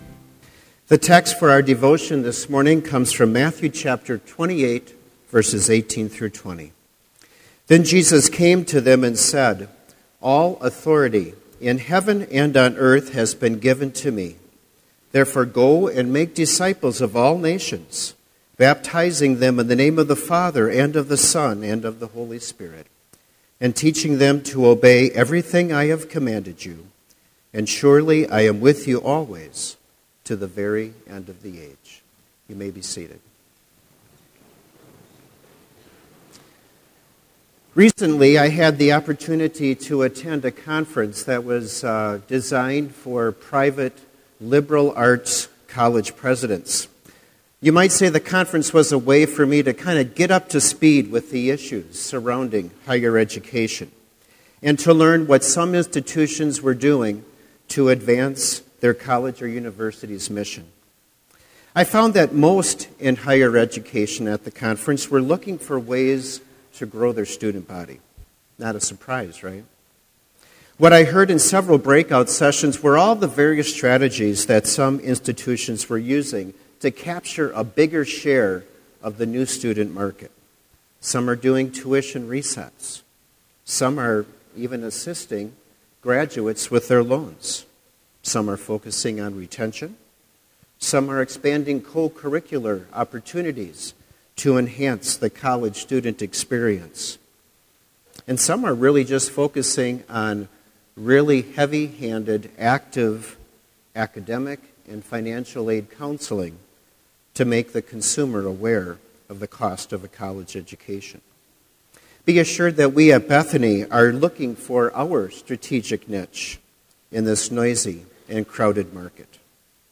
Sermon audio for Chapel - January 25, 2016